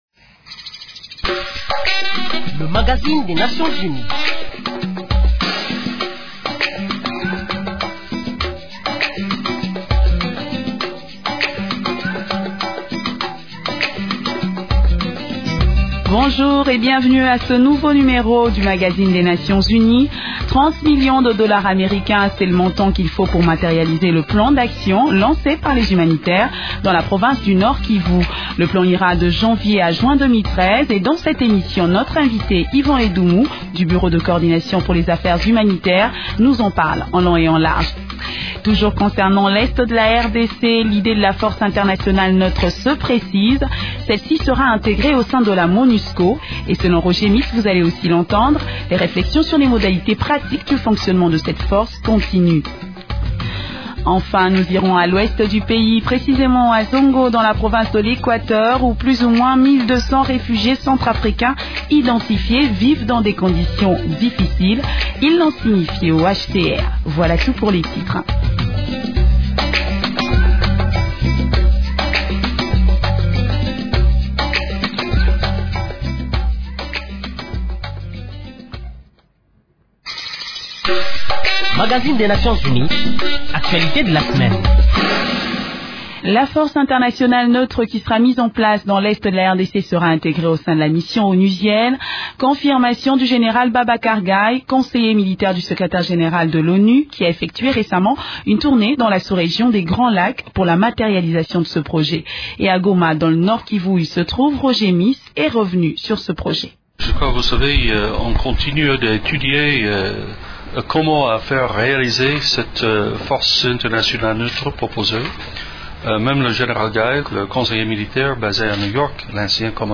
Nouvelles en bref